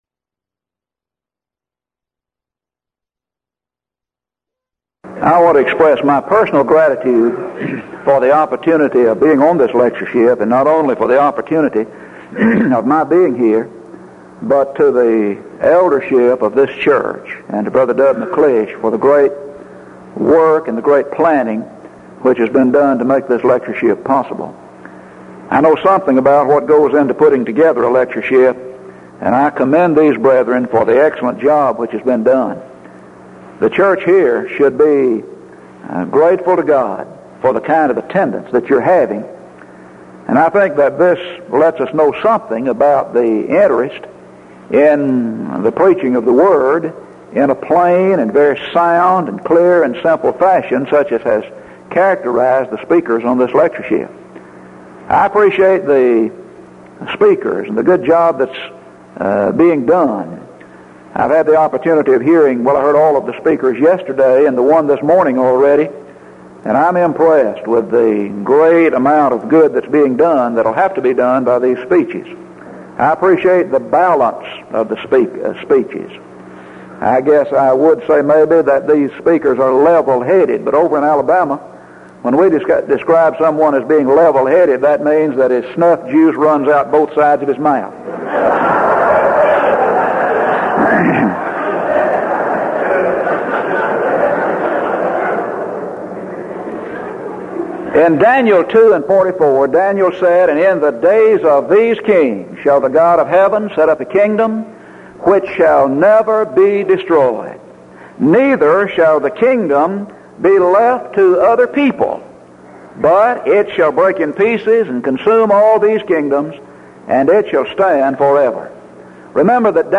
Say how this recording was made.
Event: 1983 Denton Lectures Theme/Title: Studies in Hebrews